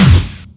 click.wav